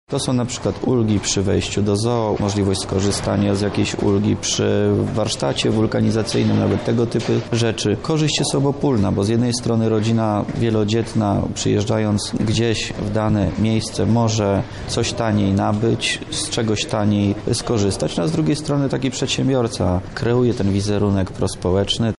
Tym programem rząd chce poprawić sytuację rodzin wielodzietnych – mówi Wojciech Wilk, wojewoda lubelski